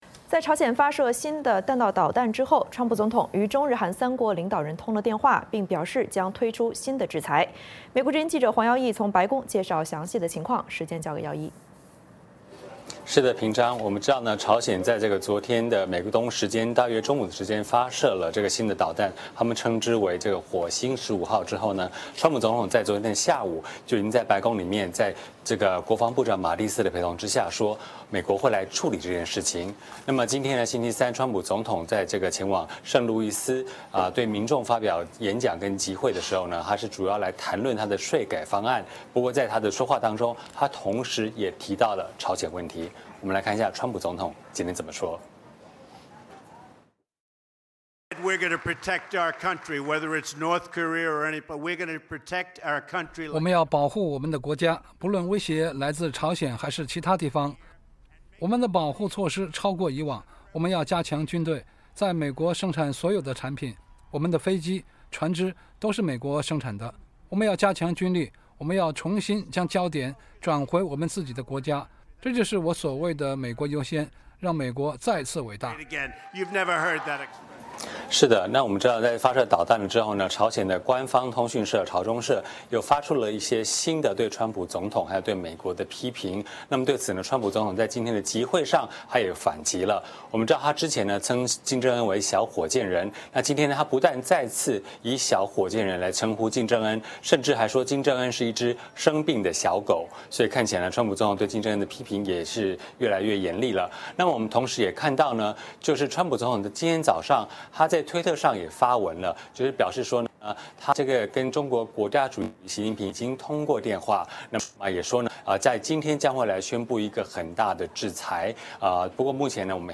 VOA连线：朝鲜射“火星15号”后，川普批金正恩